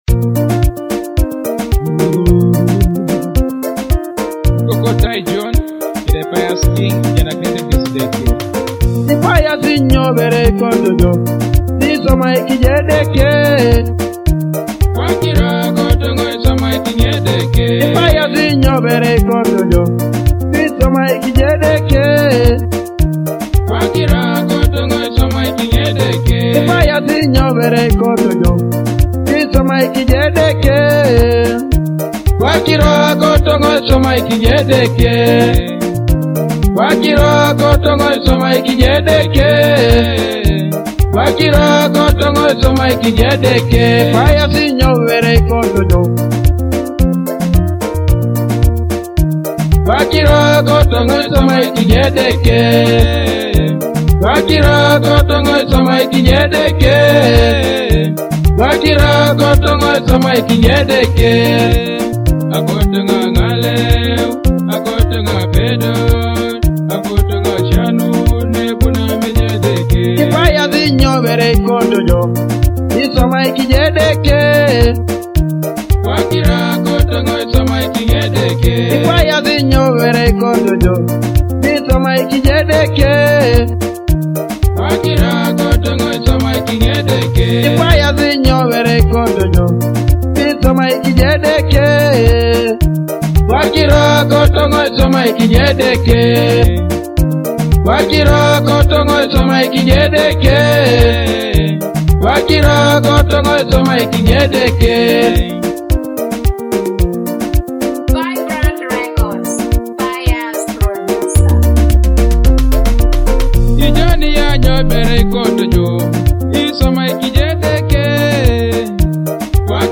an uplifting gospel praise track
offering a seamless way to enjoy powerful gospel music.